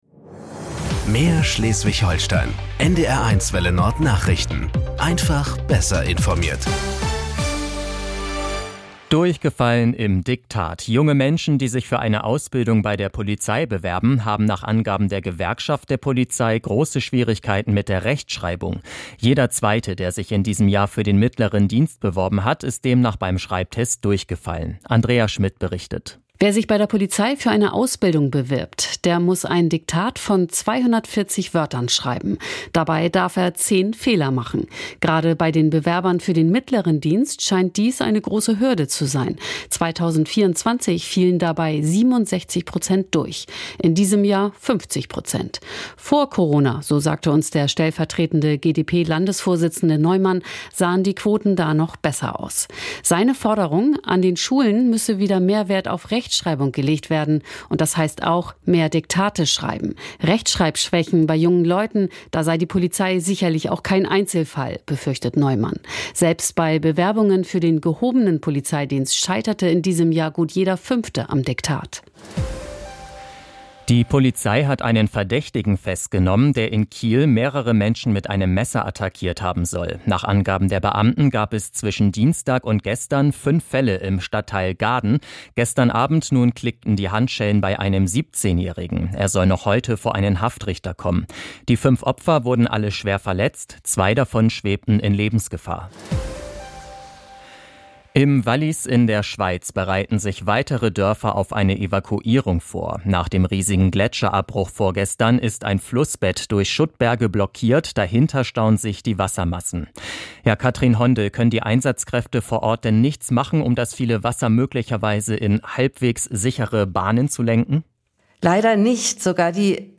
Nachrichten 20:00 Uhr.